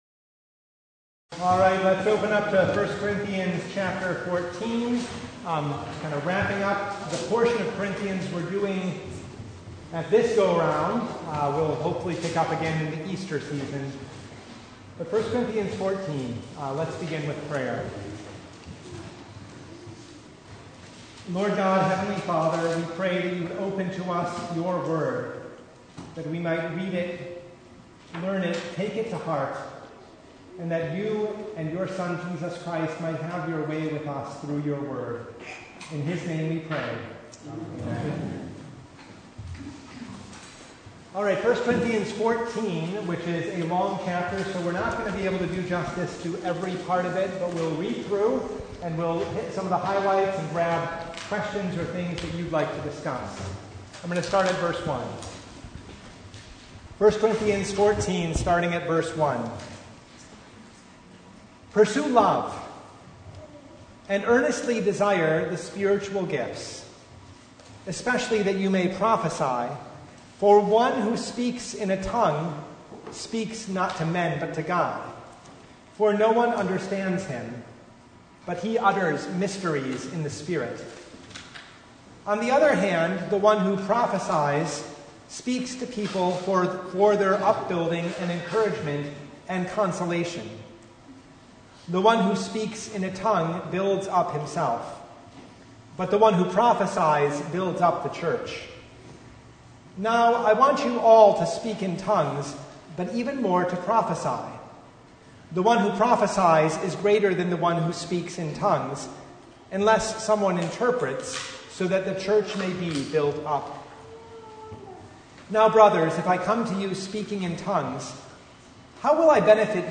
Service Type: Bible Hour
Bible Study